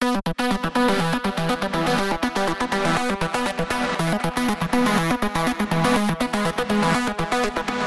Этот пресет звучит только в минорном ладе.